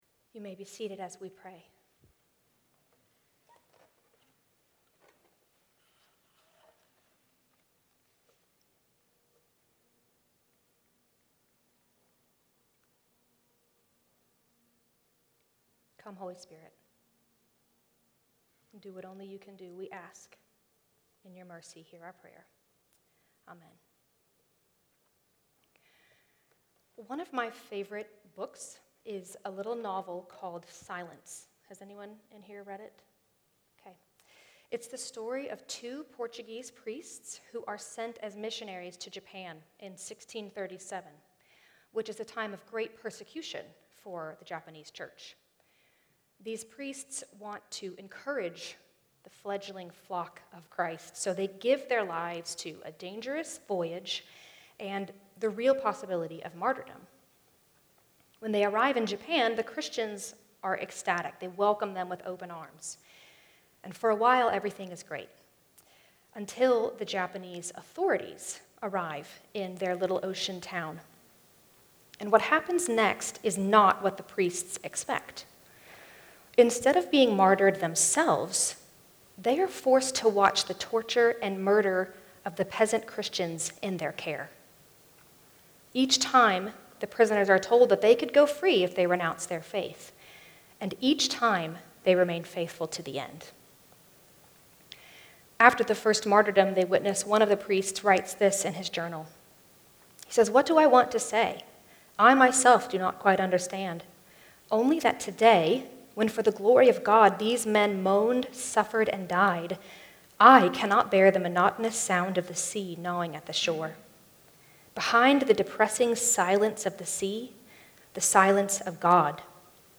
Good Friday